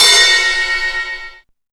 RIDE3     -L.wav